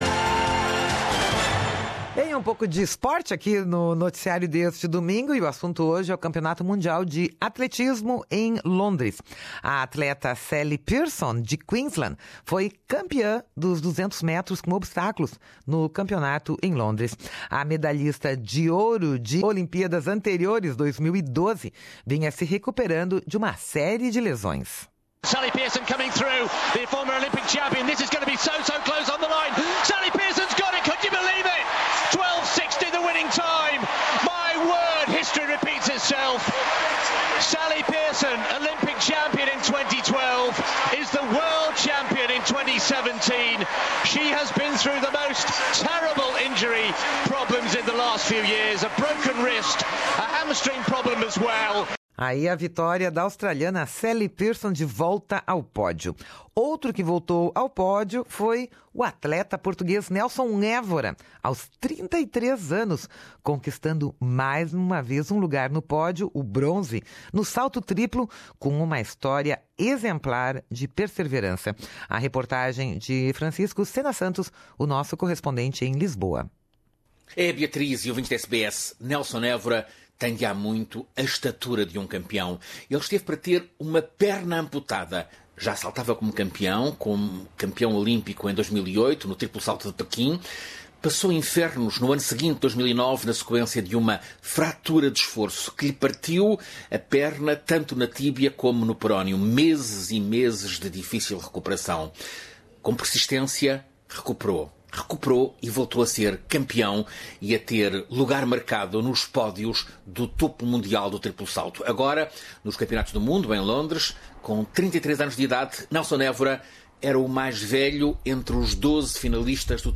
Reportagem
desde Lisboa.